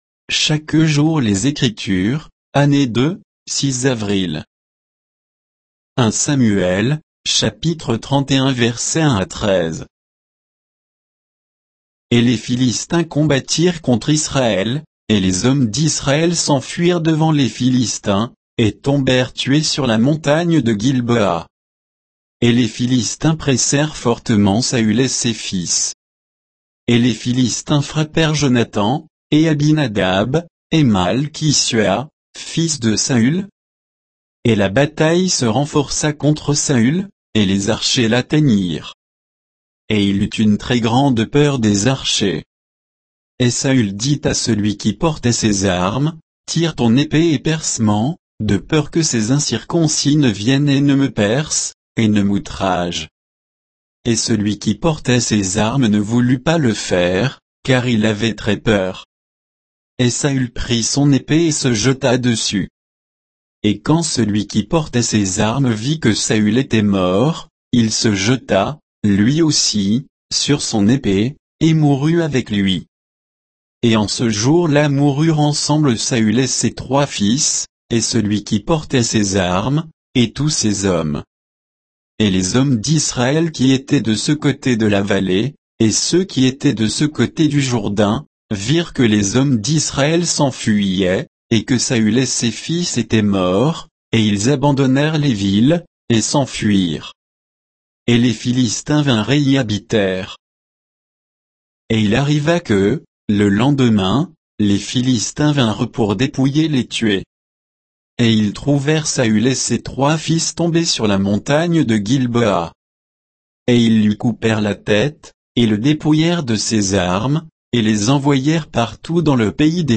Méditation quoditienne de Chaque jour les Écritures sur 1 Samuel 31, 1 à 13